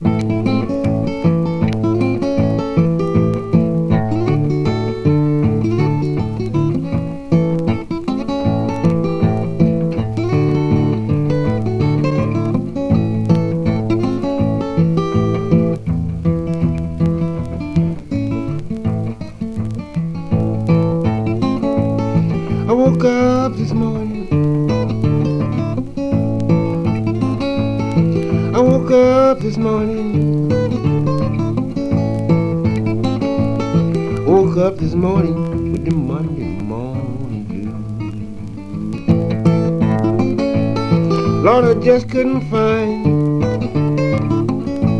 Folk, Country, Appalachian Music, Bluegrass　USA　12inchレコード　33rpm　Mono